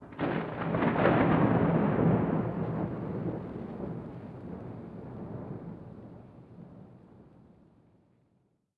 1Shot Weather Thunderclap ST450 02_ambiX.wav